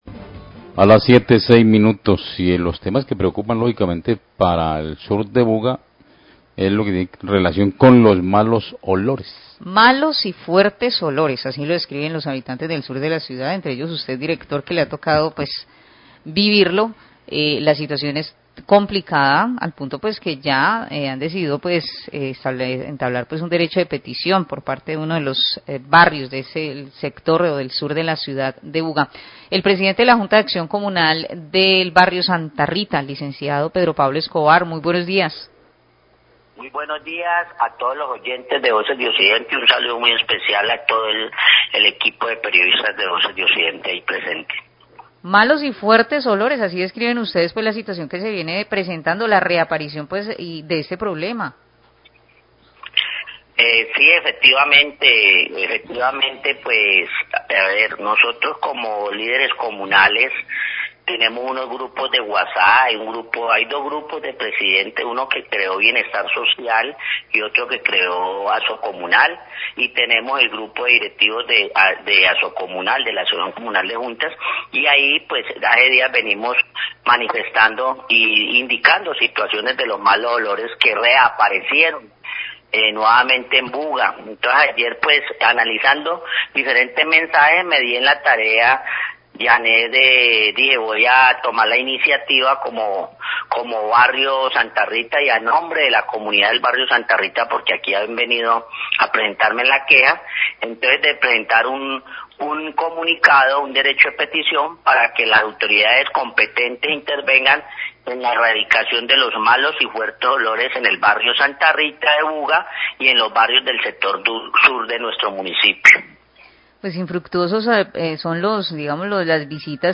Radio
Periodistas mencionan la sanción que la CVC ya había aplicado a la empresa responsable de este problema ambiental.